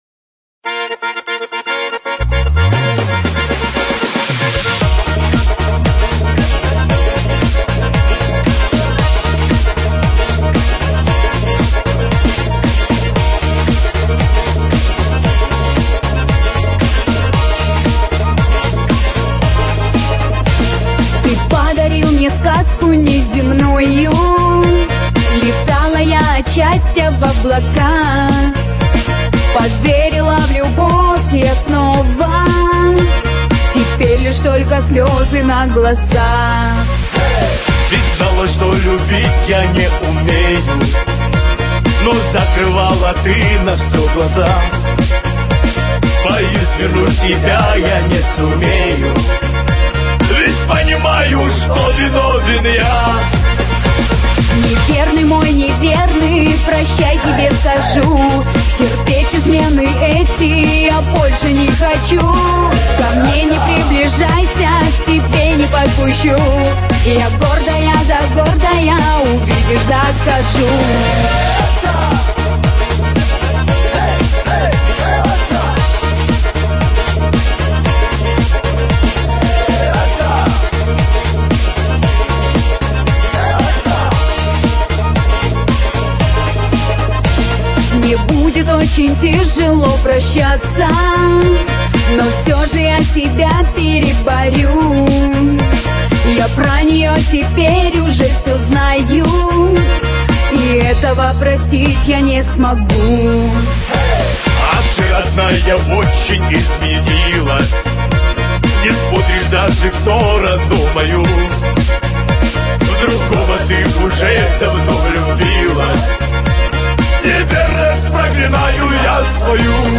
Назад в (поп)...
попса